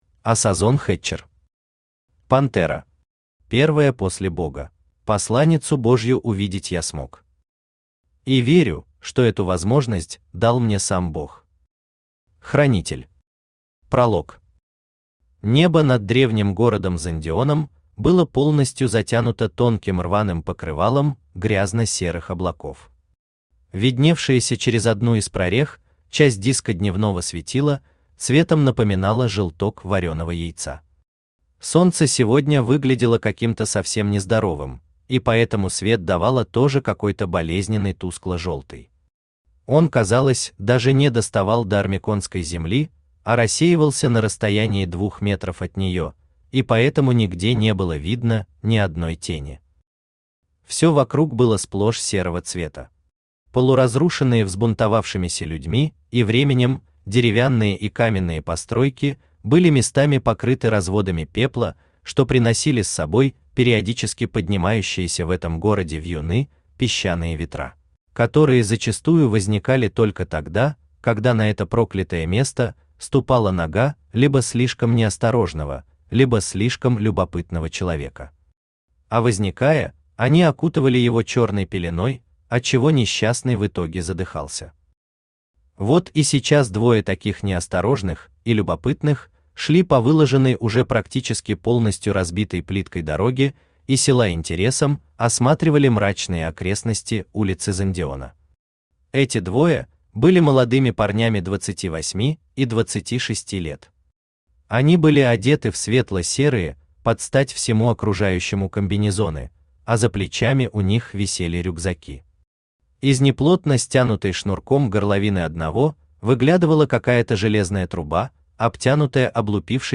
Aудиокнига Пантера. Первая после Бога Автор Асазон Хэтчер Читает аудиокнигу Авточтец ЛитРес.